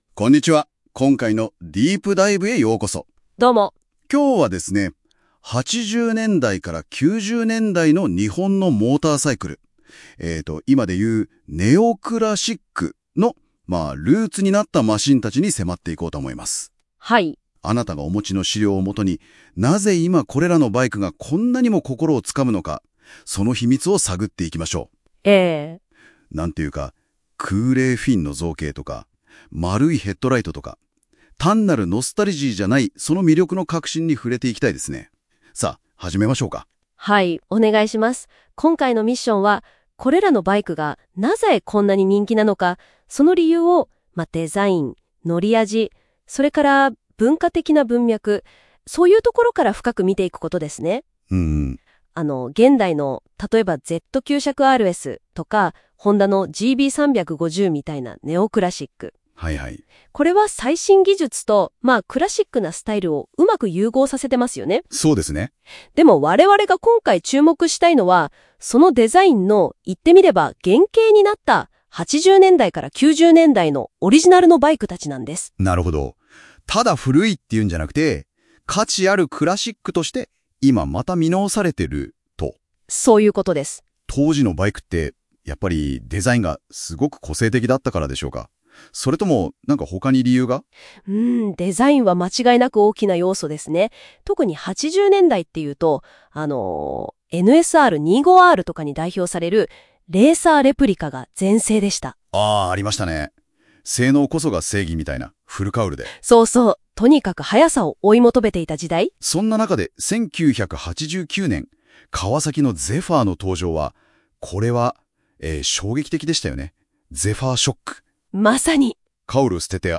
本記事をAI音声でサマリーを作成しました。※AIの漢字の読み間違いがあります